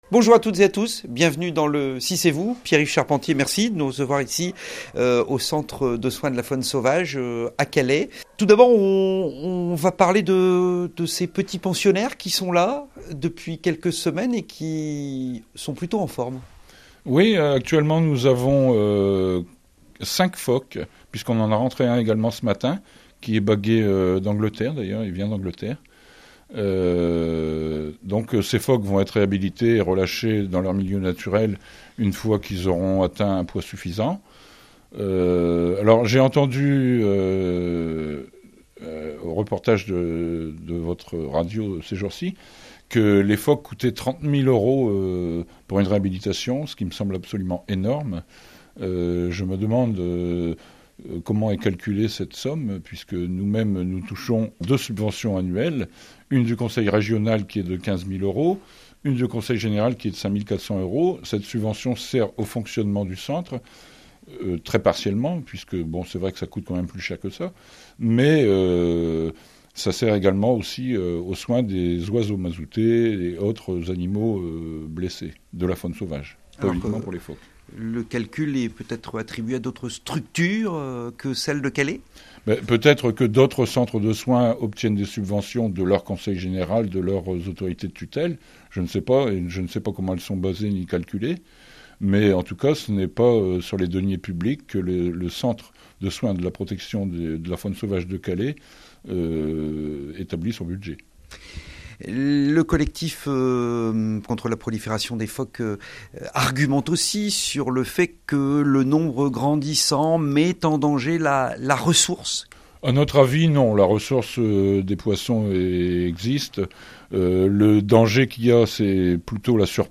Droit de réponse ce midi sur RADIO6 ! Les phoques au coeur d'une polémique...